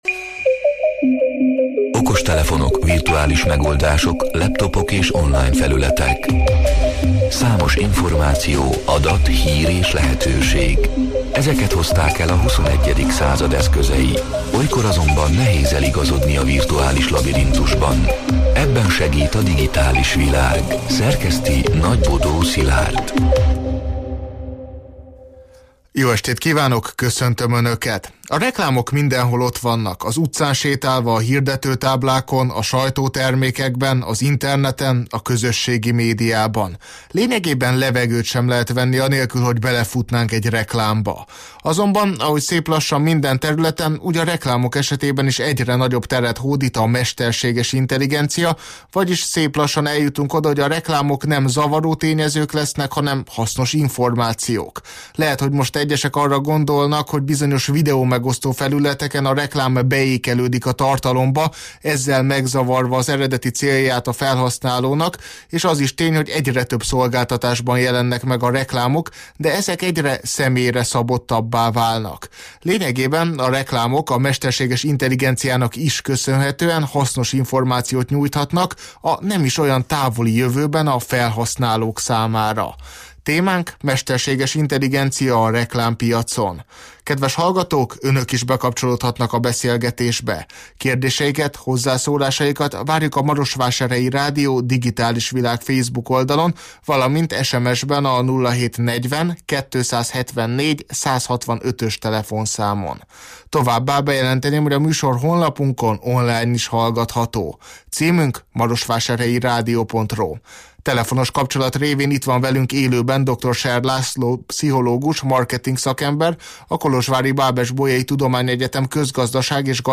A Marosvásárhelyi Rádió Digitális Világ (elhangzott: 2025. november 25-én, kedden este nyolc órától élőben) c. műsorának hanganyga: